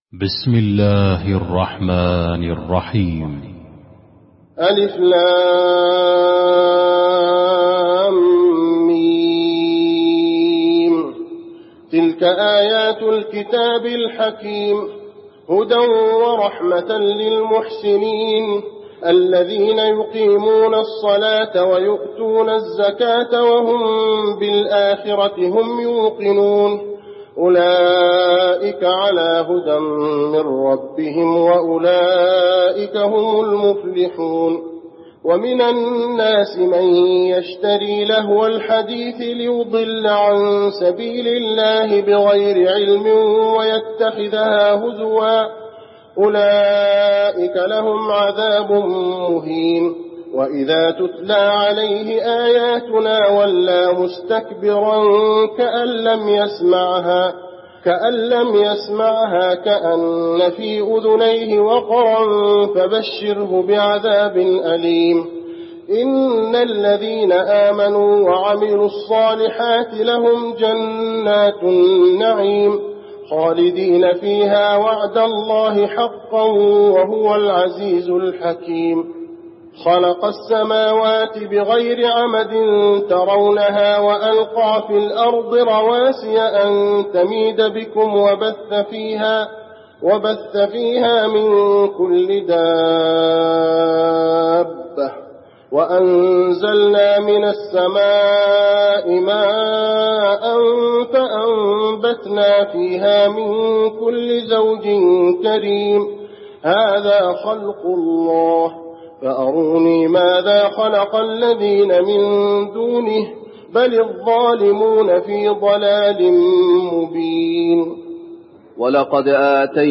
المكان: المسجد النبوي لقمان